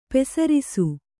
♪ pesarisu